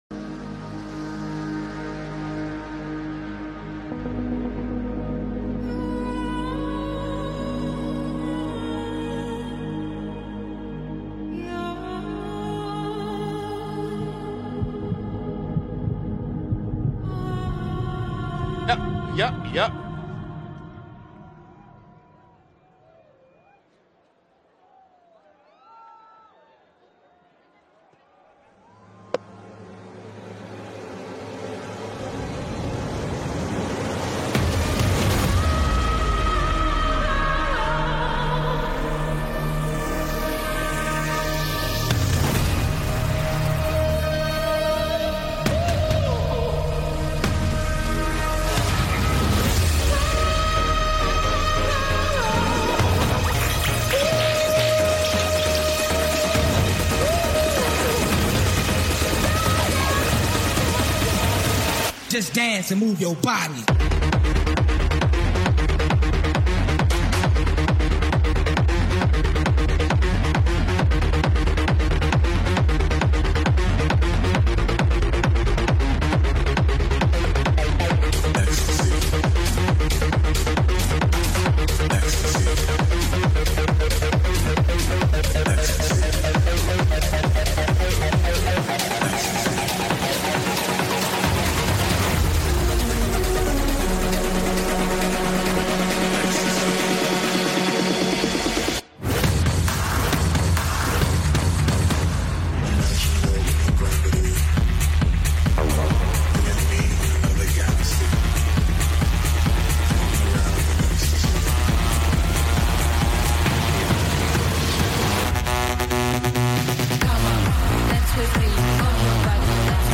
Hip-Hop DJMix Classic
Classic Rap 2000